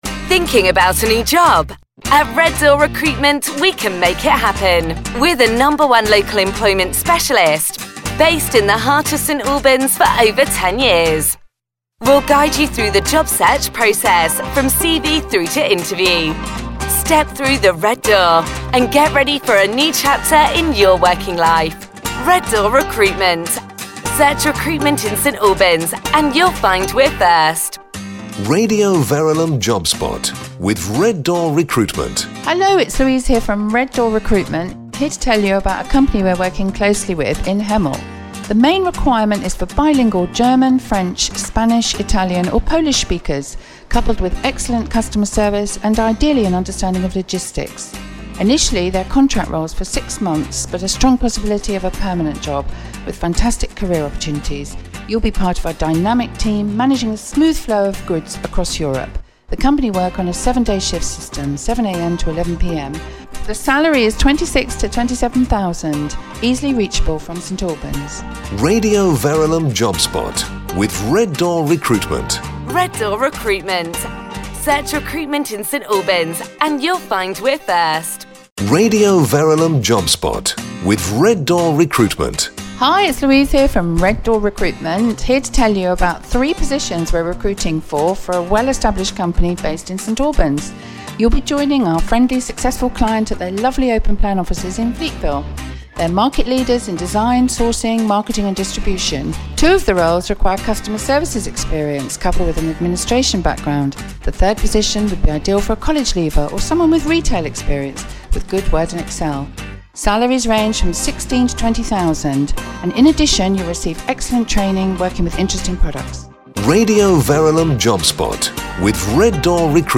Campaign combining commercial and "Job Spot" Sponsorship for St Albans' number one local recruitment specialist Red Door Recruitment on Radio Verulam